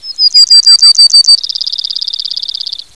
sparrow1.wav